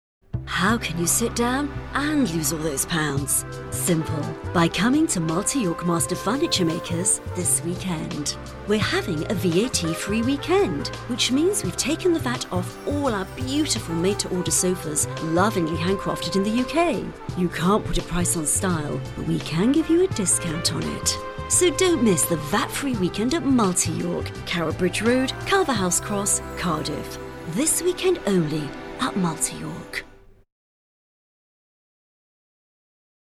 Sprechprobe: Werbung (Muttersprache):
Very easy to work with, takes direction very well, adaptable, flexible, diverse, range of styles from Warm, Friendly, Sexy to Serious, Sad, Corporate.